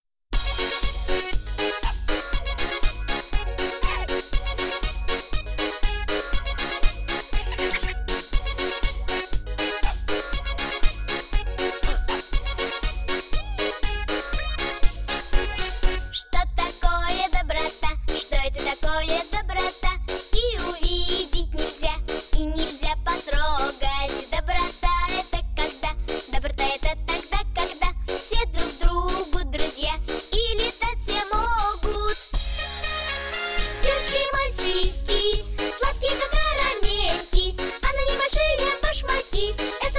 добрые
детский голос
детские